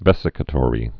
(vĕsĭ-kə-tôrē)